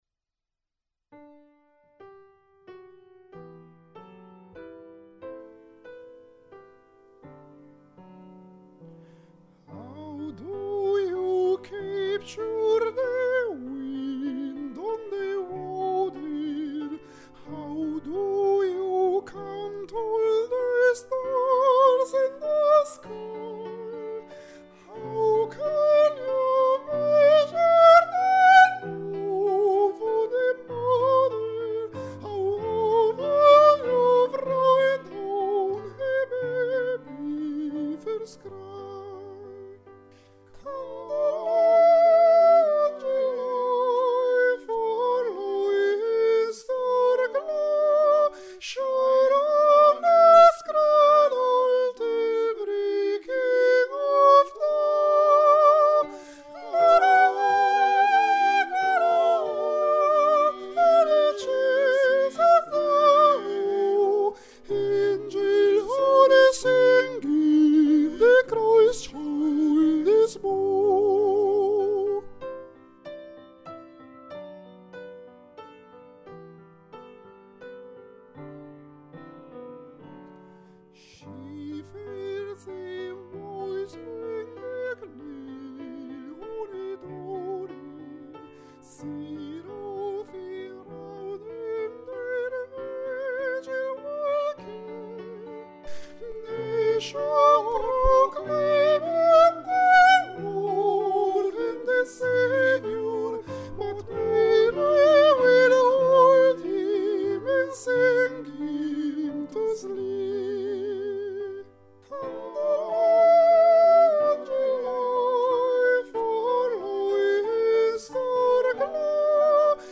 Parti per i soprani primi
Candlelight Carol (sopr. I)